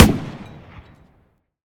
tank-mg-shot-1.ogg